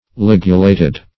\Lig"u*la`ted\ (l[i^]g"[-u]*l[=a]`t[e^]d), a. [Cf. F. ligul['e].